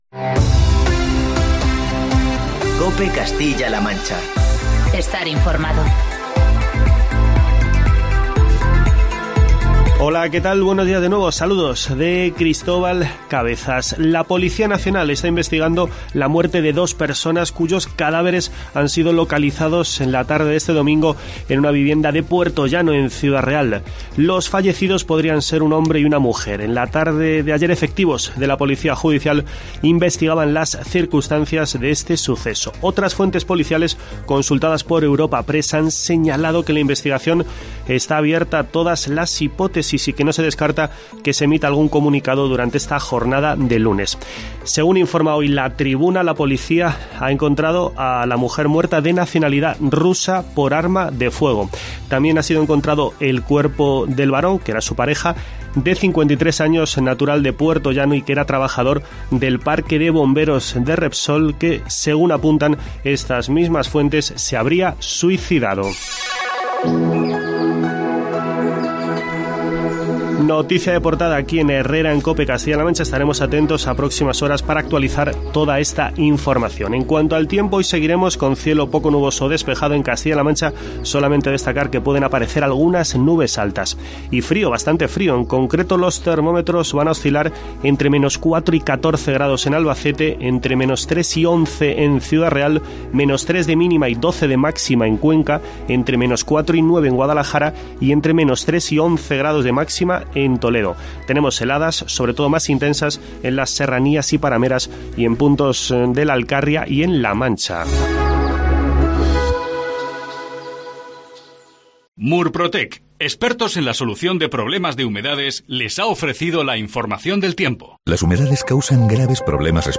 Escucha en la parte superior de esta noticia el informativo matinal de COPE Castilla-La Mancha de este lunes, 13 de enero de 2010.